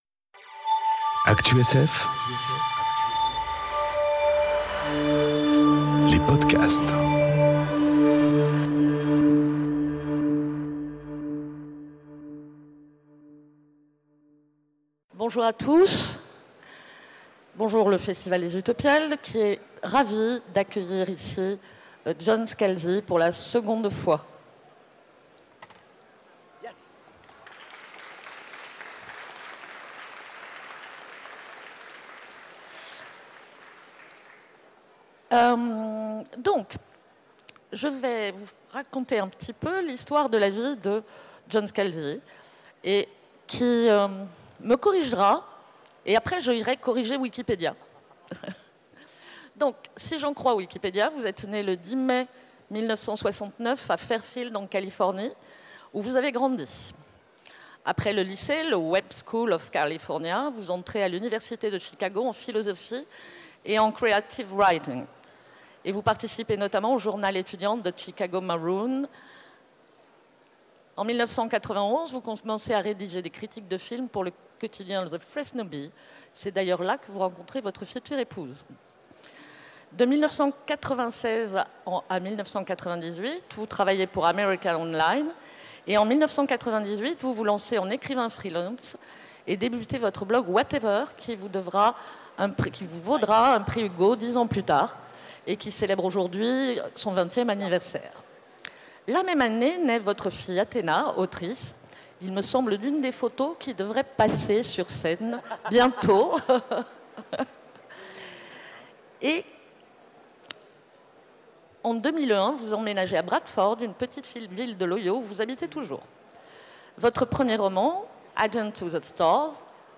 Rencontre avec John Scalzi enregistrée aux Utopiales 2018